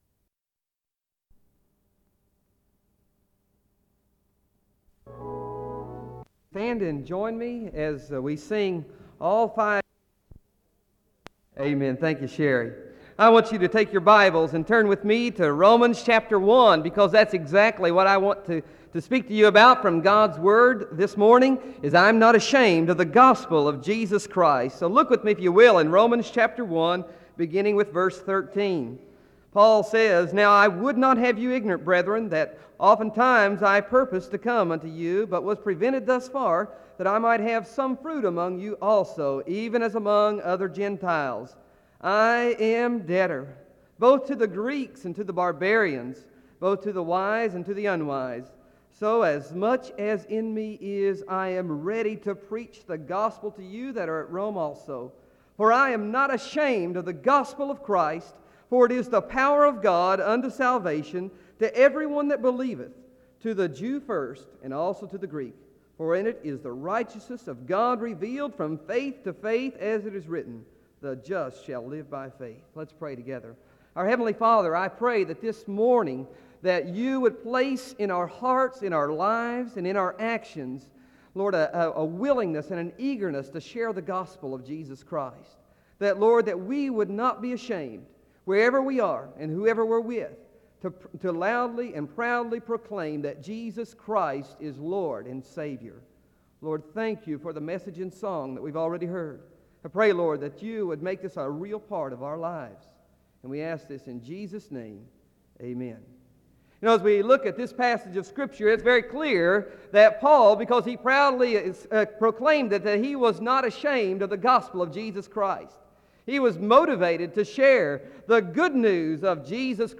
SEBTS Chapel